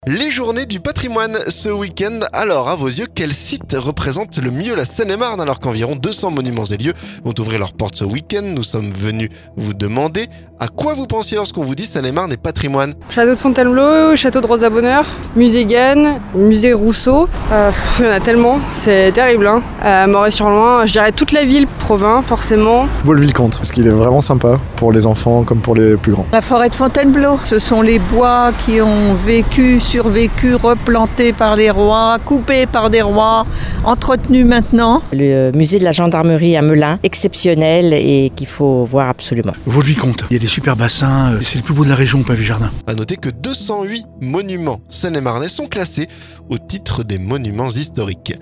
Quels sites représentent le mieux la Seine-et-Marne ? Alors qu'environ 200 monuments et lieux vont ouvrir leurs portes ce week-end, nous sommes venus vous demander à qui vous pensiez lorsqu'on vous dit Seine-et-Marne et Patrimoine.